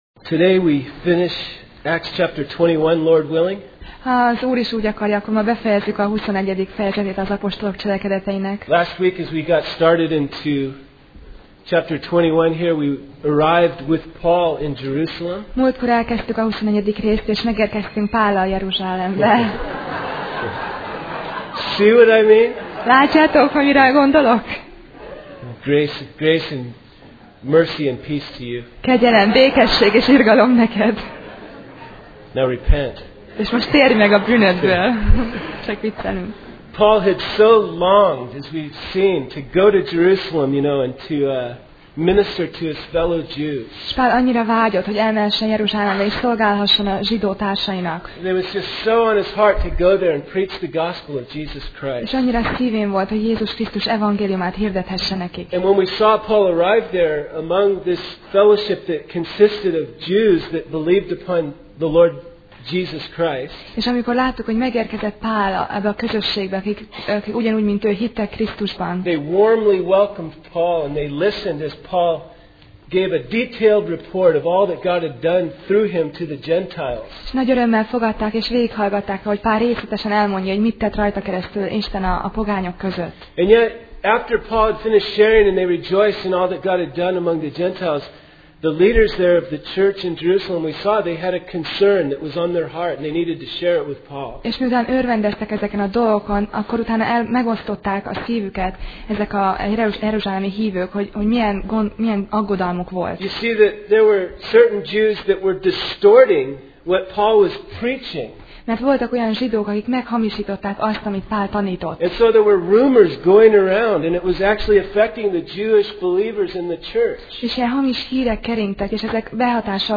Sorozat: Apostolok cselekedetei Passage: Apcsel (Acts) 21:27-40 Alkalom: Vasárnap Reggel